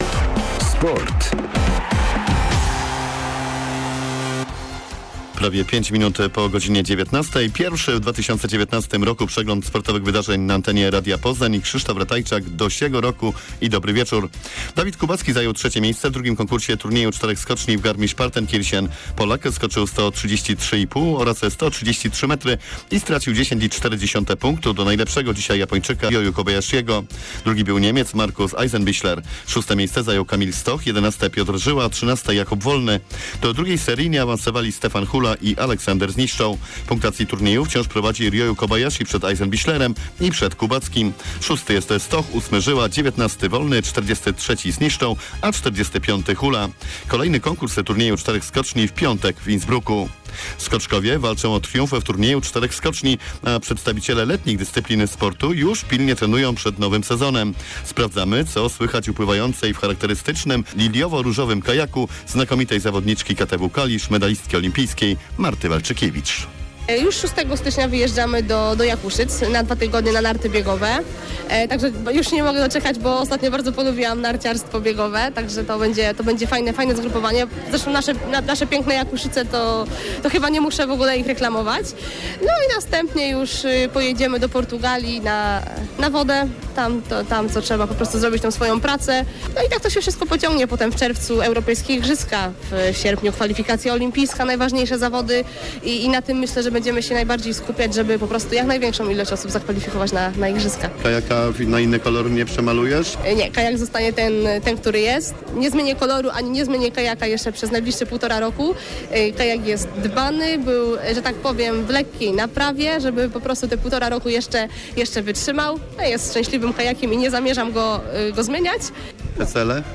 01.01. serwis sportowy godz. 19:05